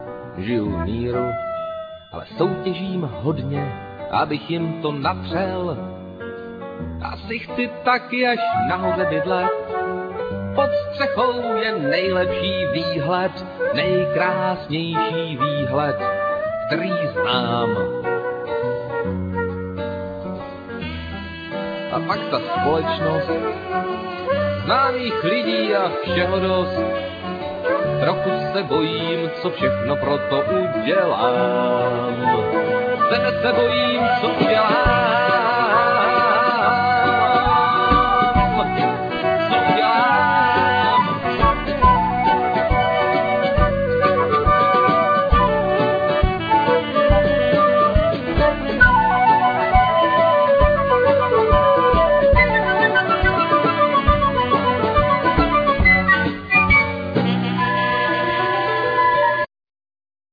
Piano,Keyboards,Guitar,Trumpet,Vocal,etc
Cello,Saxophone,Vocal,etc
Flute,Piano,Keyboards,etc
Drums,Percussions,Vocal,etc